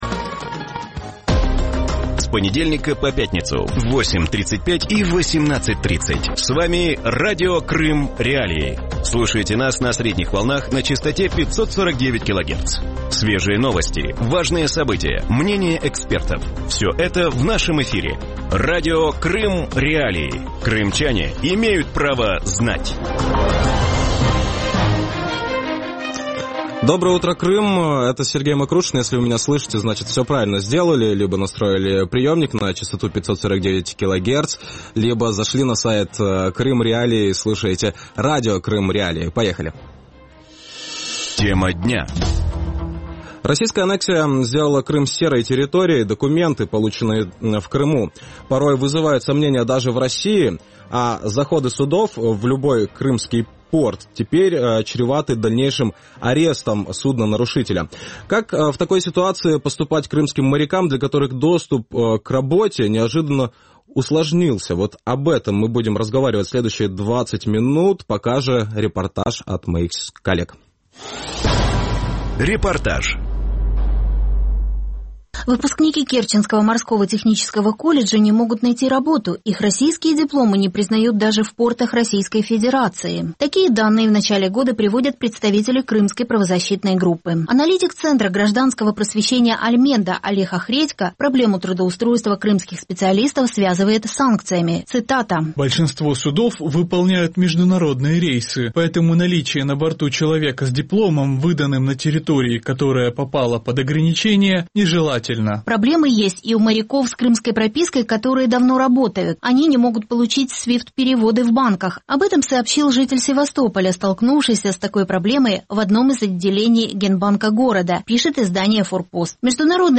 Вранці в ефірі Радіо Крим.Реалії говорять про те, як російська анексія зробила Крим "сірої територією" для міжнародного морського права.